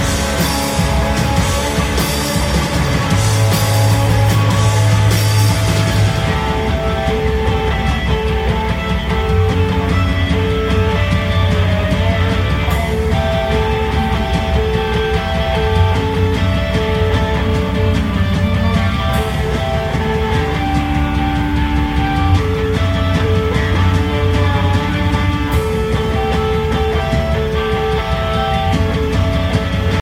com concerto integral ao vivo no Cabaret Metro em Chicago